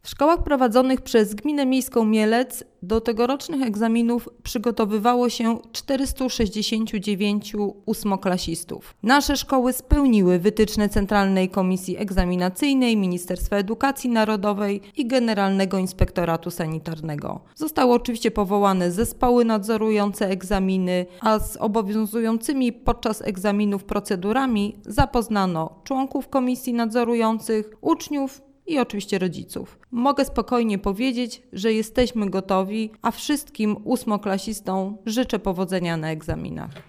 Mówi Adriana Miłoś, wiceprezydent Mielca.